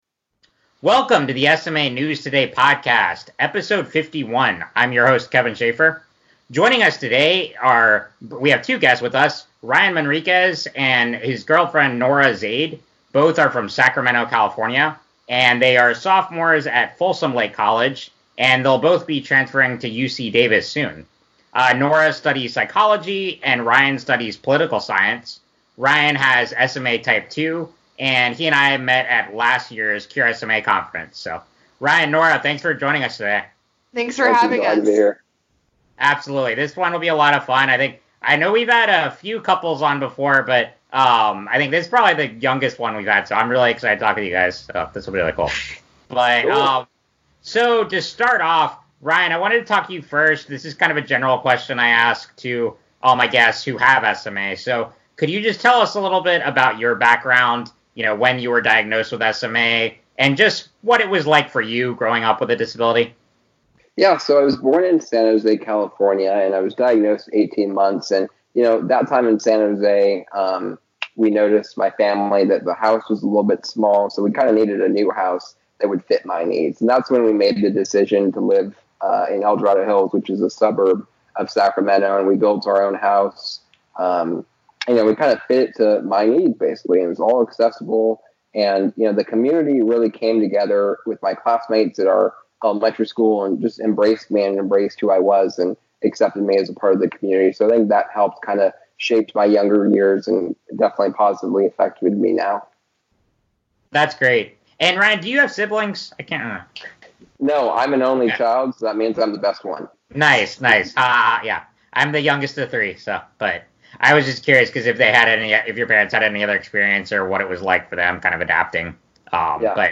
#51: Interview